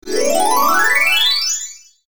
MG_sfx_travel_game_bonus.ogg